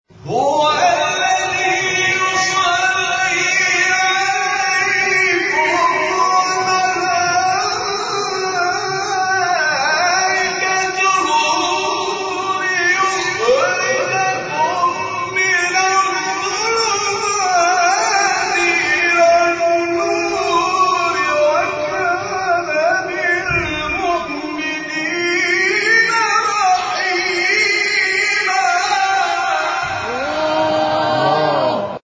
شبکه اجتماعی: فرازهای صوتی از تلاوت قاریان ممتاز کشور را می‌شنوید.
سوره احزاب در مقام رست